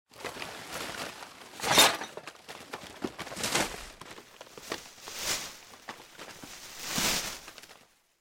garbage_soft_0.ogg